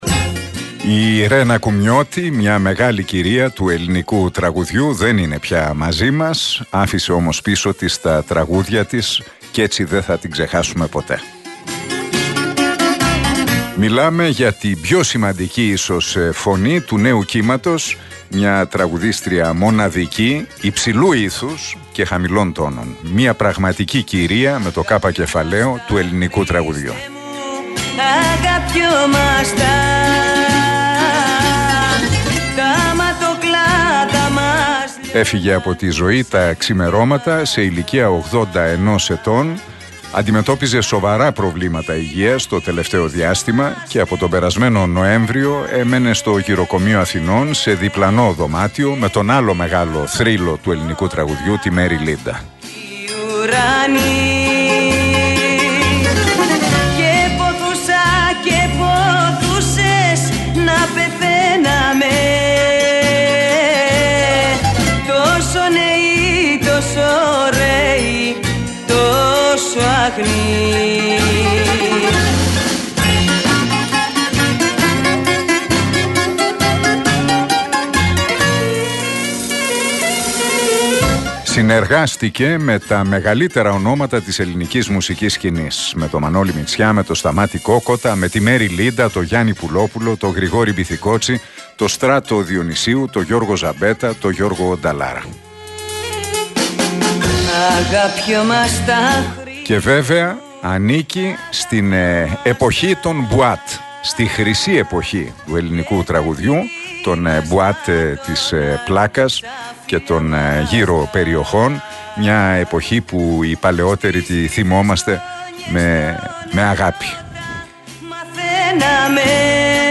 Ακούστε το σχόλιο του Νίκου Χατζηνικολάου στον RealFm 97,8, την Δευτέρα 3 Απριλίου 2023.